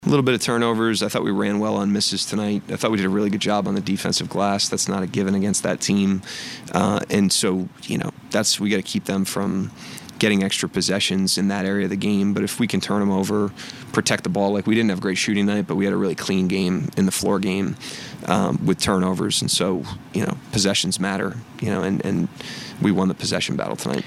Thunder head coach Mark Daigneault talks about his club’s ability to get out and run.